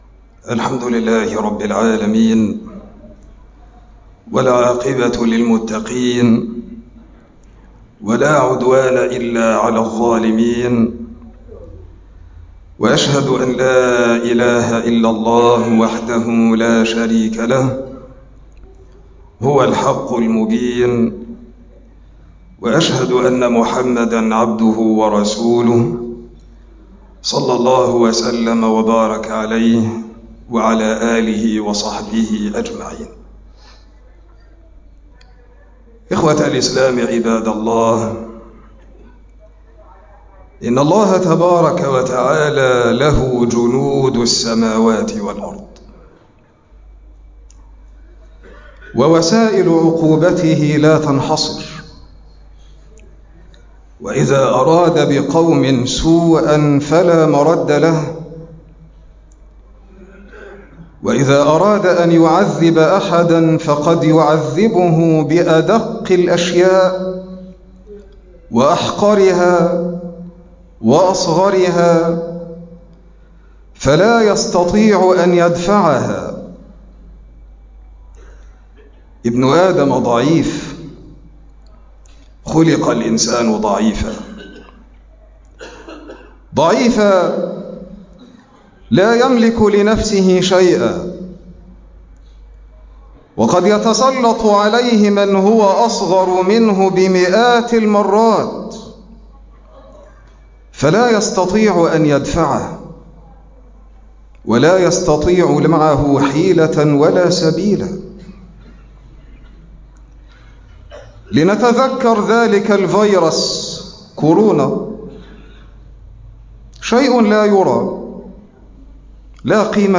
مقطع من خطبة الجمعة (حرمة المال العام)